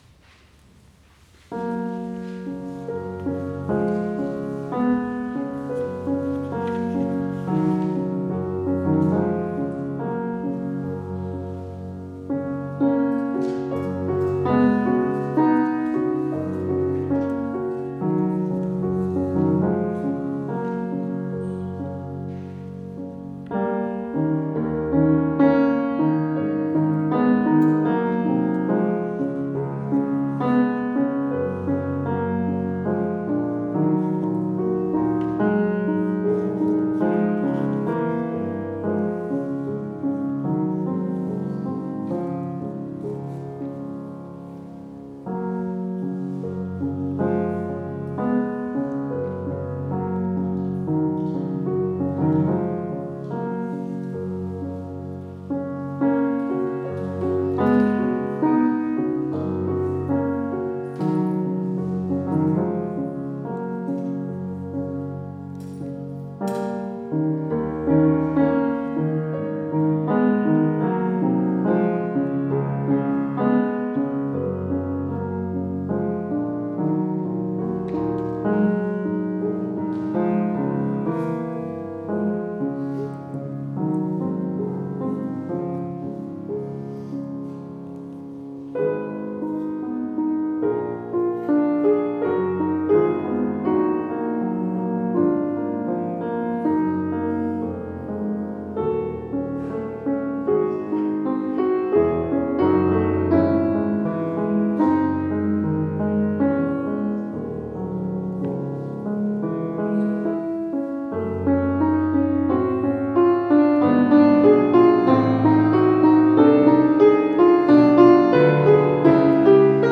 Romanze Op. 28, 2 – Robert Schumann 24 nov 2024 (live)